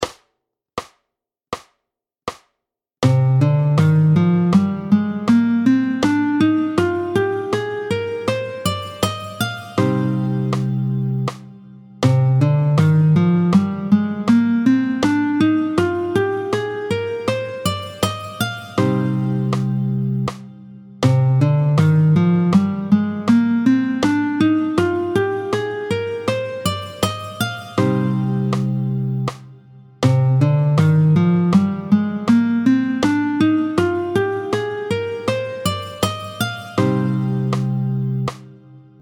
Le mode (et le doigté VI) : do ré mib fa sol lab sib do, est appelé l’Aéolien.
27-06 Le doigté du mode de Do aéolien, tempo 80
27-06-Do-aeolien.mp3